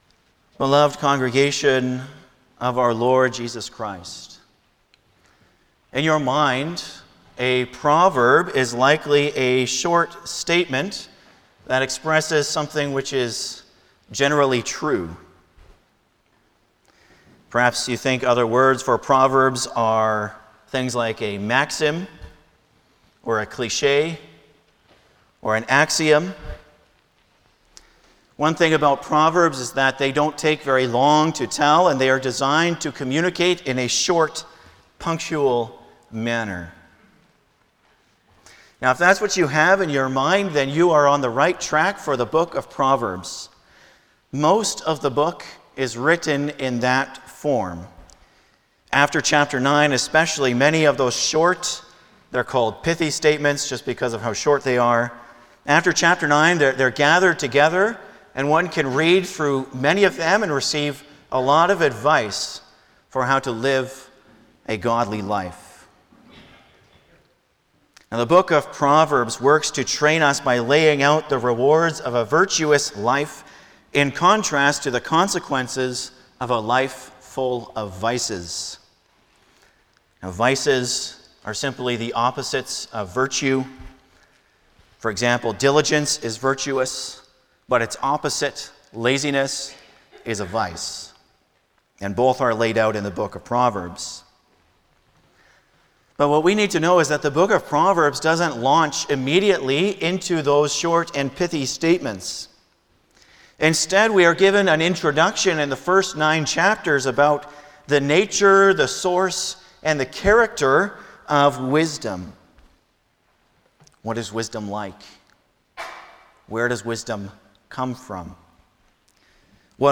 Passage: Proverbs 1:1-7 Service Type: Sunday morning
09-Sermon.mp3